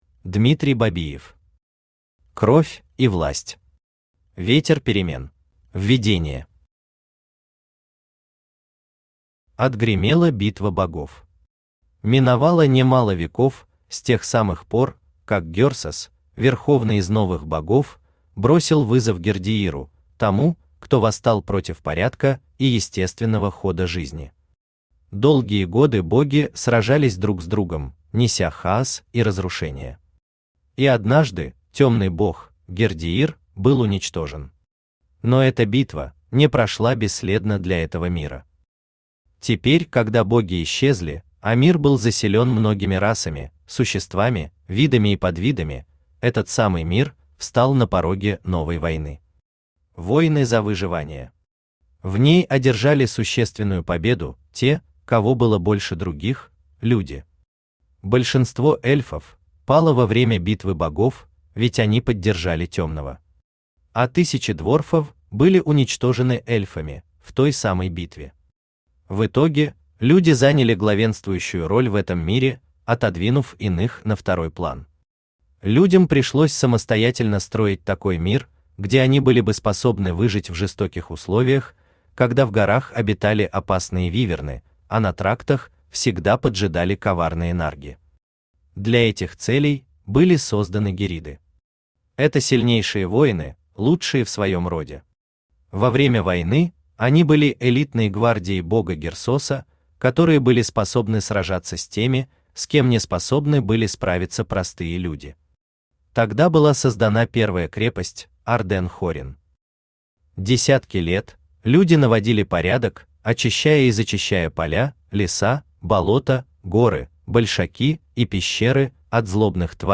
Аудиокнига Кровь и Власть. Ветер перемен | Библиотека аудиокниг
Aудиокнига Кровь и Власть. Ветер перемен Автор Дмитрий Евгеньевич Бабиев Читает аудиокнигу Авточтец ЛитРес.